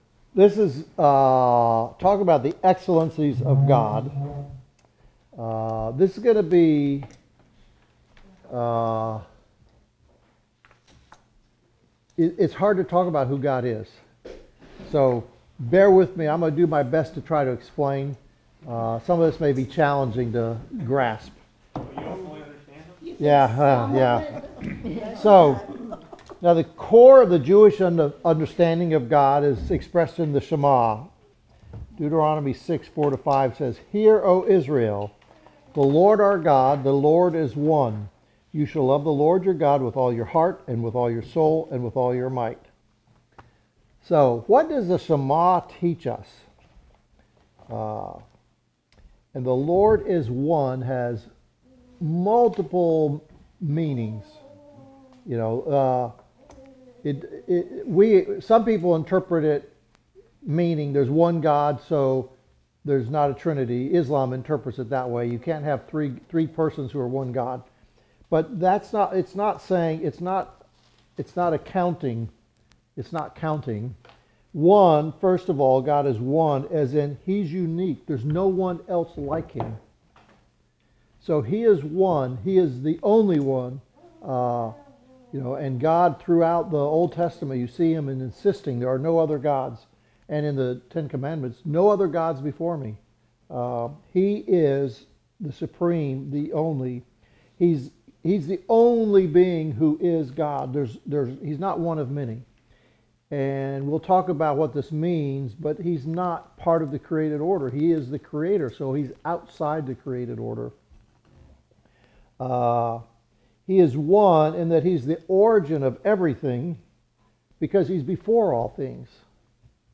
Worship Seminar